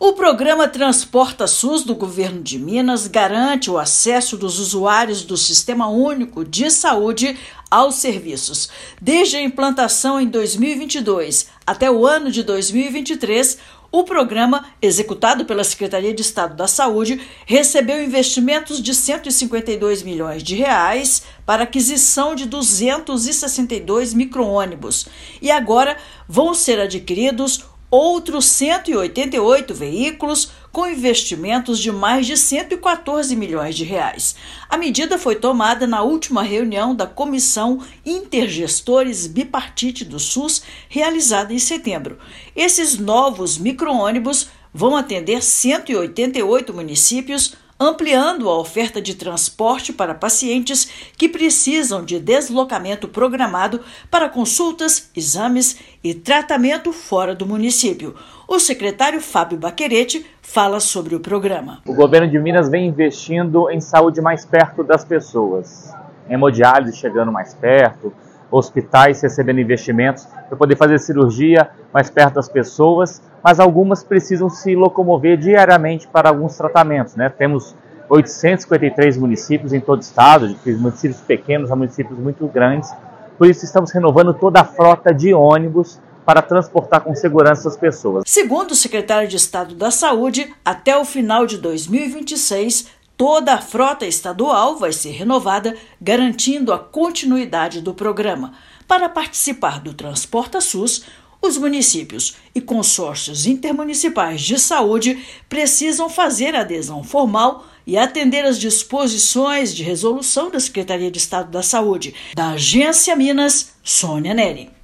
Com investimento de mais de R$ 260 milhões para atender 429 municípios, Governo de Minas garante conforto, segurança e eficiência no transporte de pacientes para consultas e tratamentos especializados. Ouça matéria de rádio.